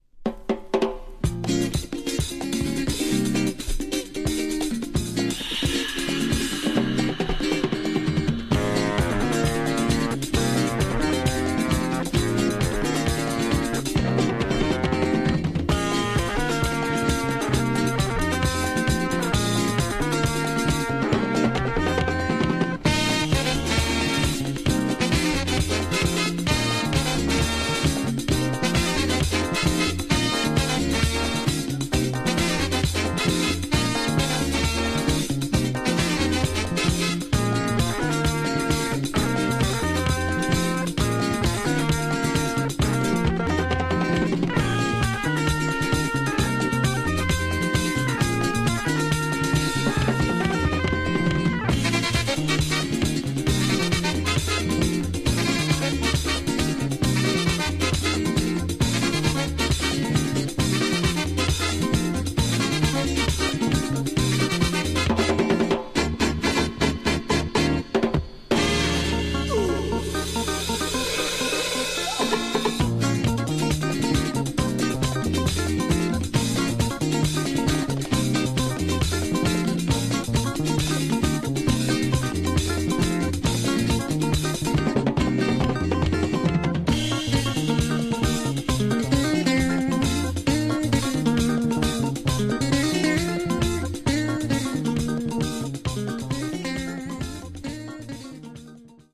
Tags: Mexico , Latin Funk